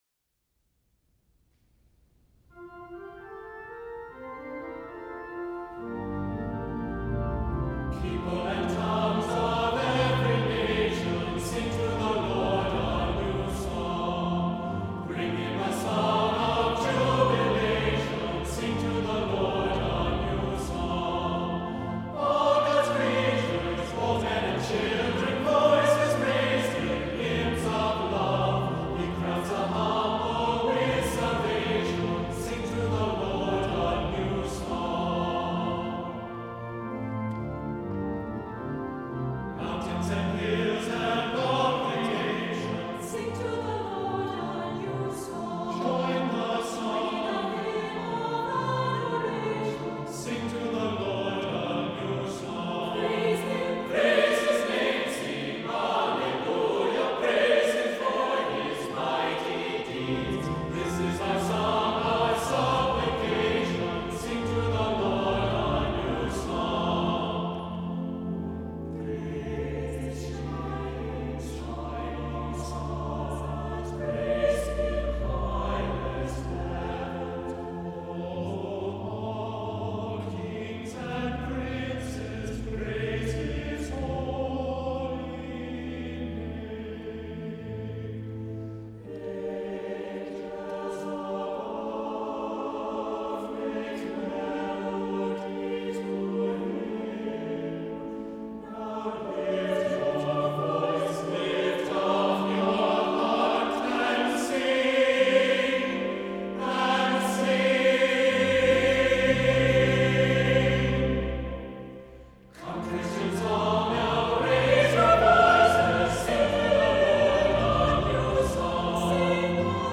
• Music Type: Choral
• Voicing: SATB
• Accompaniment: Organ
• mostly in unison with occasional, easy four-part writing
• simply, yet imaginative organ accompaniment